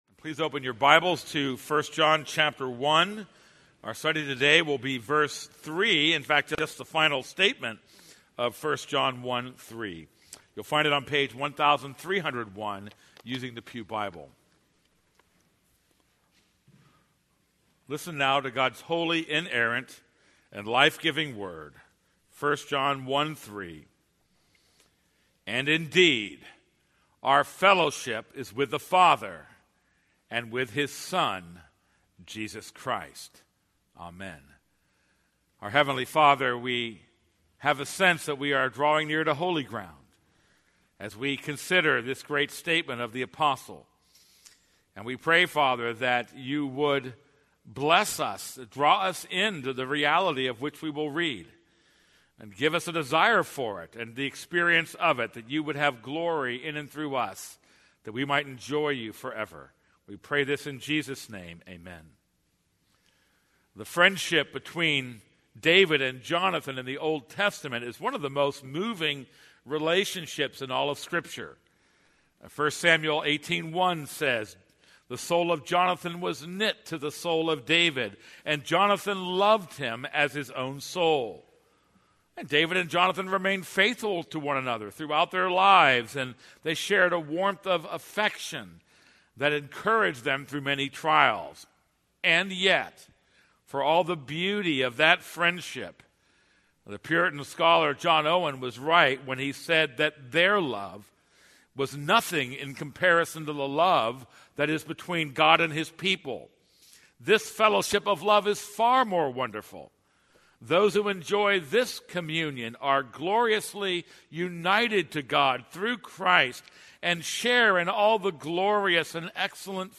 This is a sermon on 1 John 1:3.